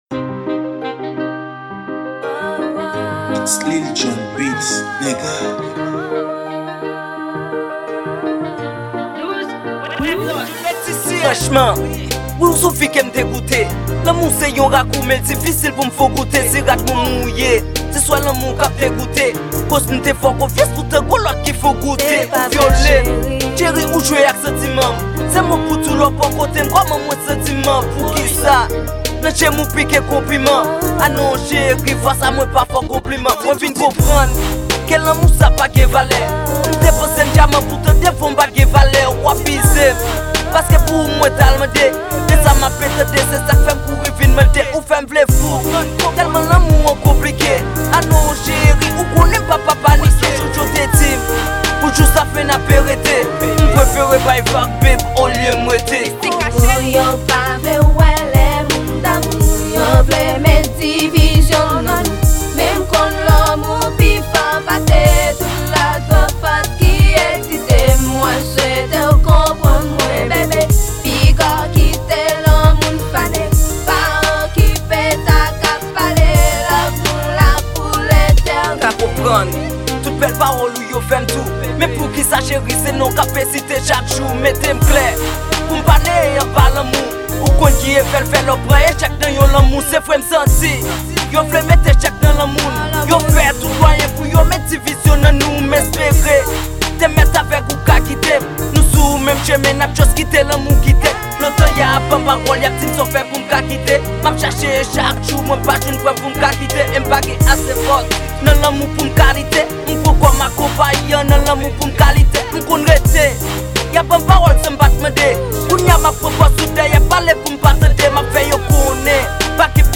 Genre: Rap & Rnb.